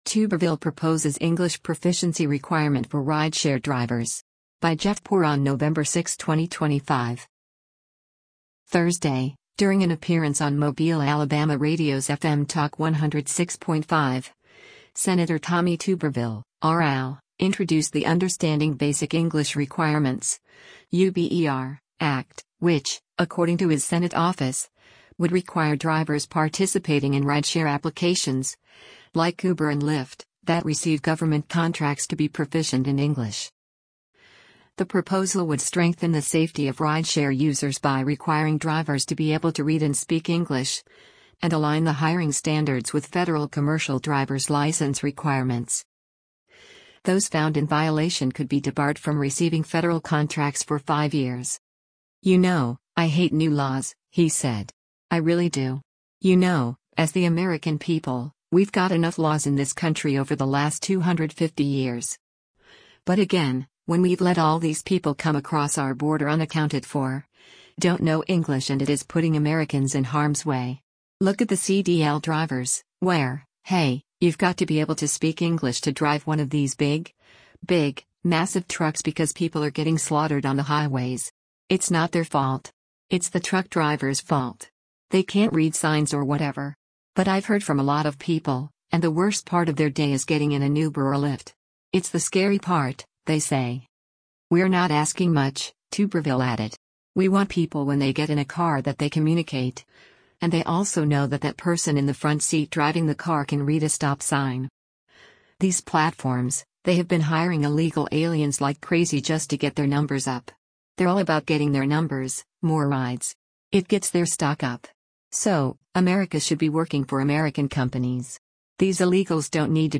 Thursday, during an appearance on Mobile, AL radio’s FM Talk 106.5, Sen. Tommy Tuberville (R-AL) introduced the Understanding Basic English Requirements (UBER) Act, which, according to his Senate office, would require drivers participating in rideshare applications, like Uber and Lyft, that receive government contracts to be proficient in English.